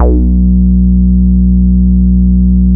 FROG BASS 4.wav